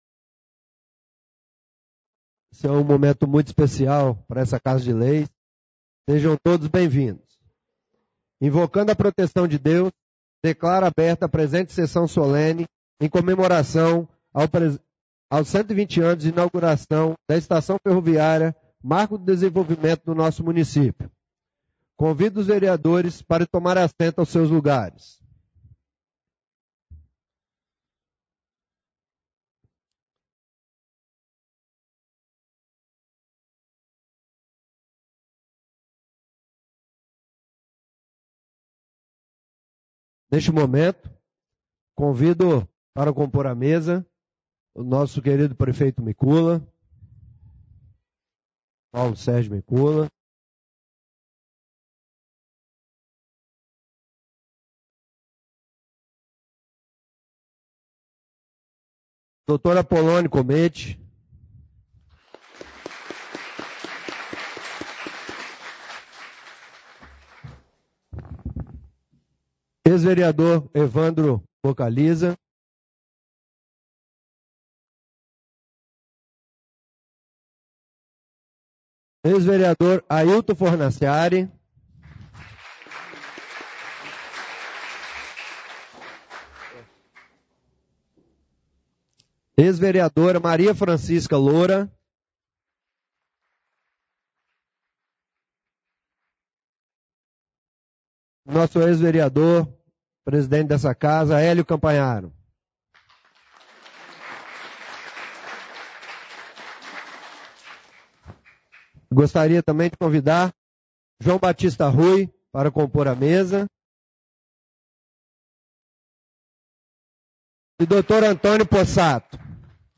Sessao Solene comemoração 120 anos inauguração estação ferroviária